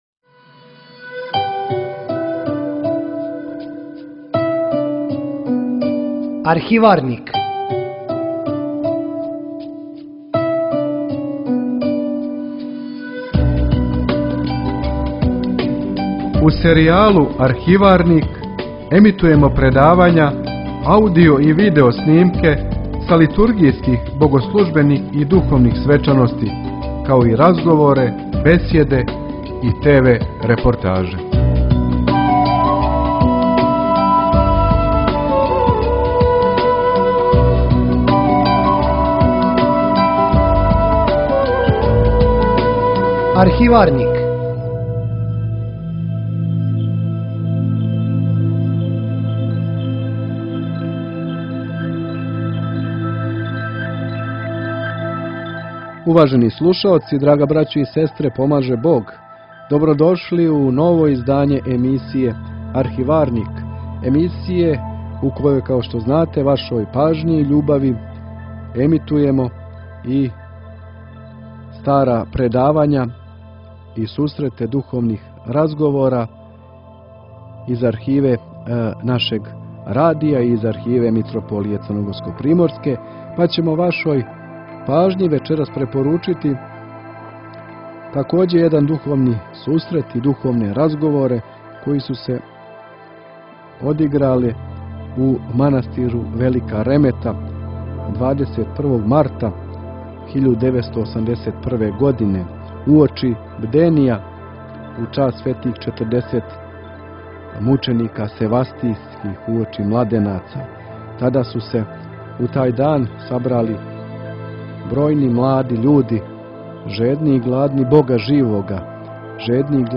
У двадесет деветој емисији серијала Архиварник емитујемо аудио снимак са духовног сабрања младих у манастиру Велика Ремета 1981. године. На сабору је предавање одржао тадашњи професор Богословског Факултета у Београду јеромонах др. Амфилохије Радовић.
Звучни запис предавања Извор: Радио Светигора